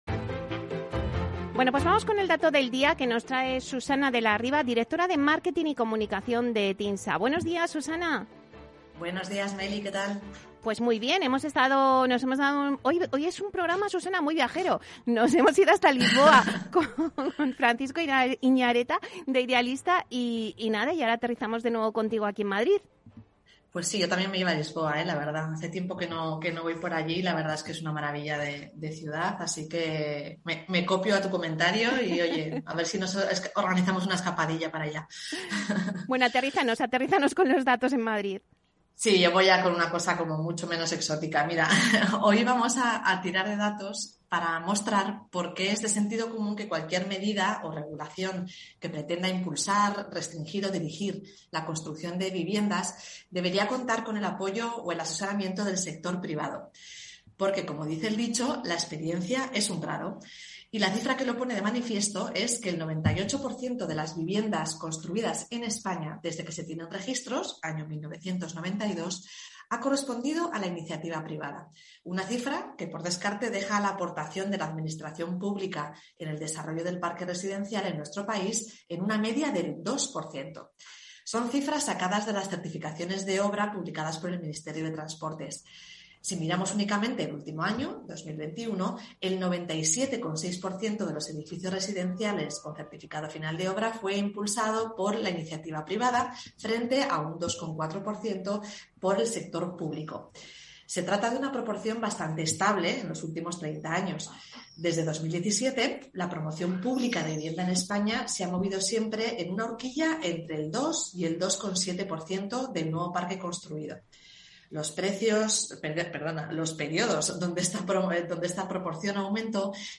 Así lo hemos comentado en la sección El Dato de Capital Radio: